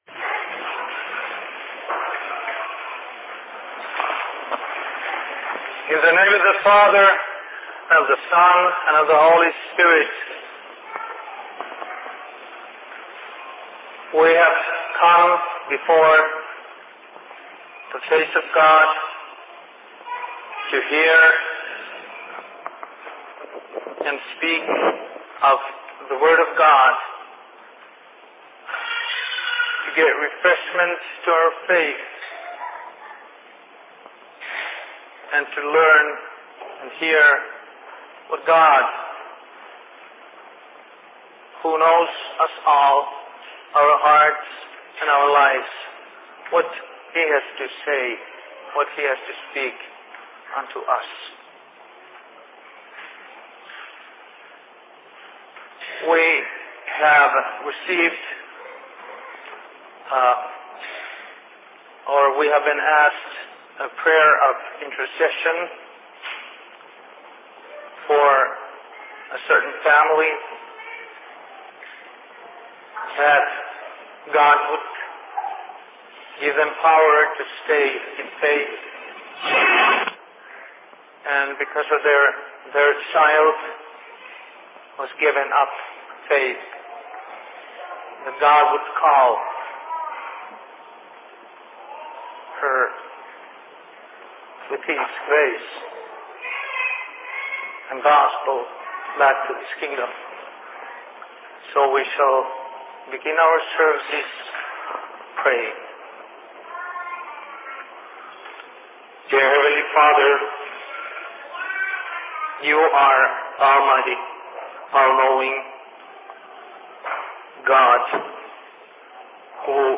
Sermon in Minneapolis 25.05.2006